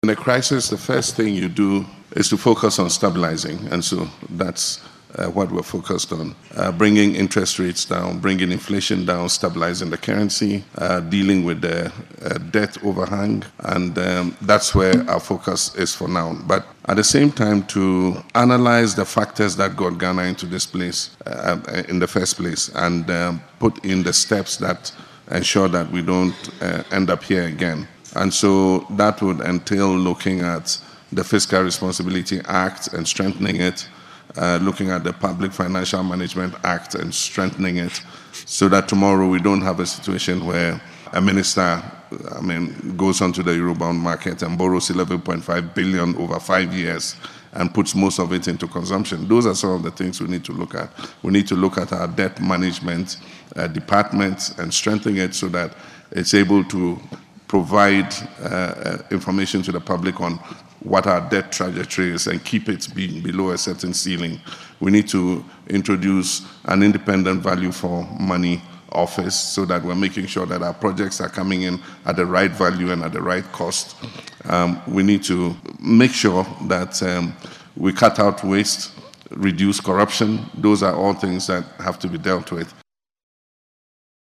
Speaking at the Africa Business Forum 2025 in Addis Ababa, Ethiopia on Monday, February 17, President Mahama emphasised that his government is focused on stabilising the cedi, tackling inflation, and addressing other critical economic challenges.